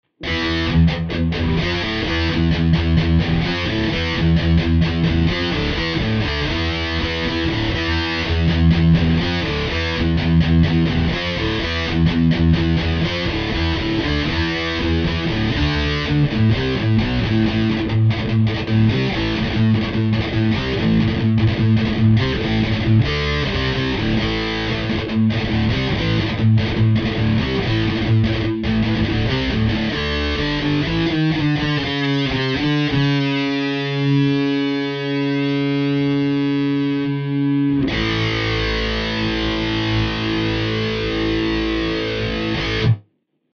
Пример звука Revolver (distortion)
Записано на гитаре Fender Squier
distortion.mp3